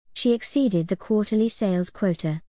▶アメリカ英語 (tのflapping有り)
▶イギリス英語 (tのflapping無し)